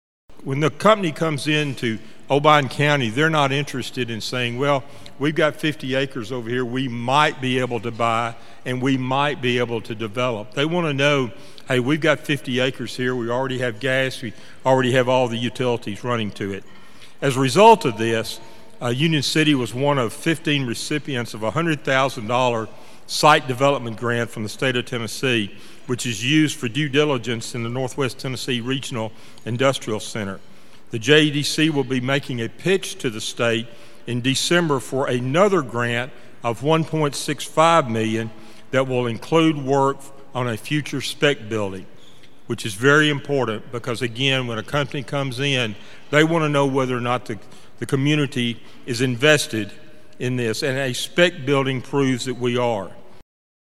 The Obion County Joint Economic Development Council and Chamber of Commerce held their annual banquet last week.
The banquet was held in front of a sold out crowd of 300 people at Discovery Park of America.